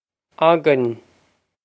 againn og-inn
Pronunciation for how to say
og-inn
This is an approximate phonetic pronunciation of the phrase.